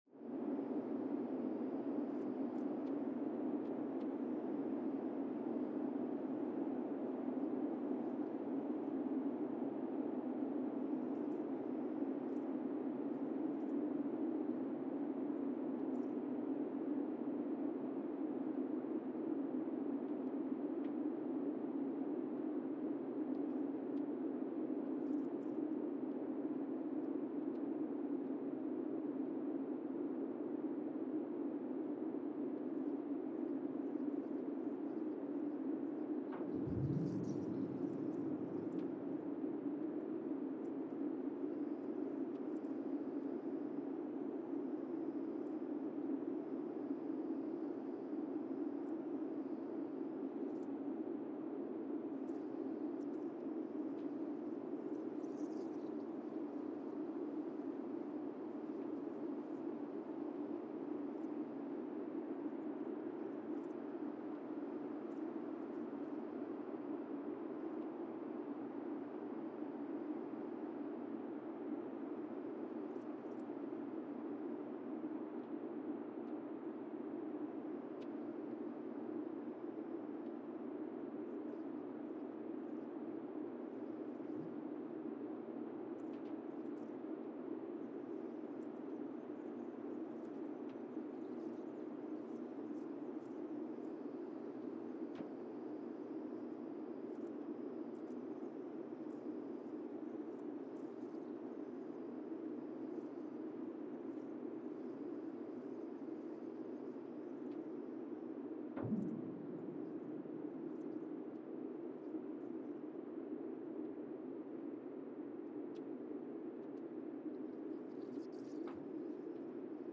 Monasavu, Fiji (seismic) archived on August 24, 2019
Sensor : Teledyne Geotech KS-54000 borehole 3 component system
Speedup : ×1,800 (transposed up about 11 octaves)
SoX post-processing : highpass -2 90 highpass -2 90